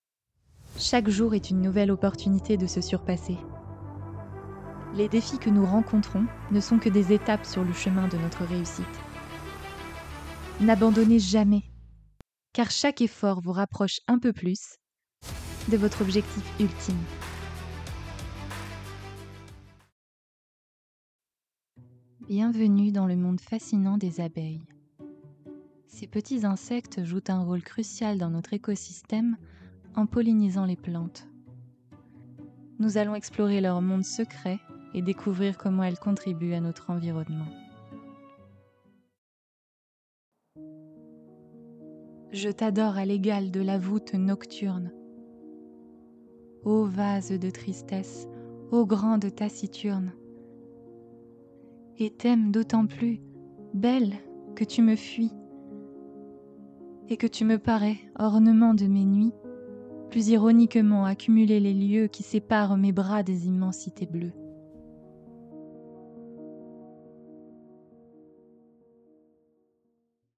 Bandes-son
6 - 45 ans - Soprano